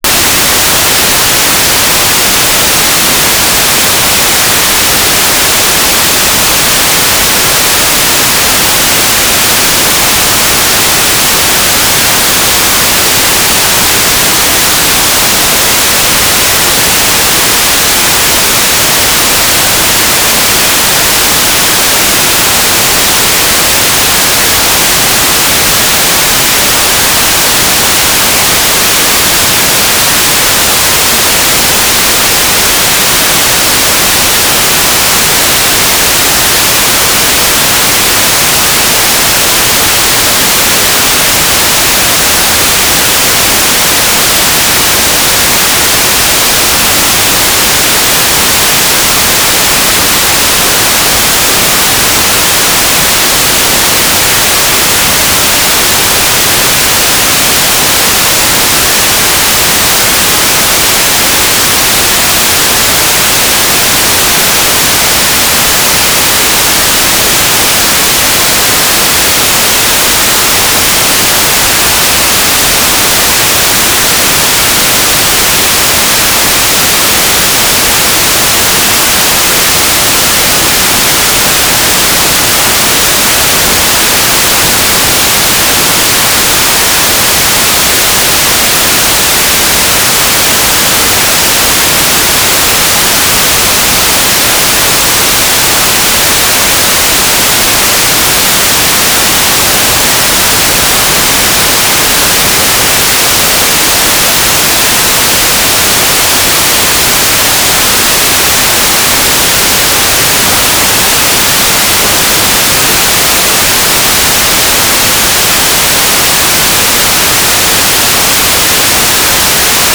"transmitter_description": "Telemetry",
"transmitter_mode": "FM",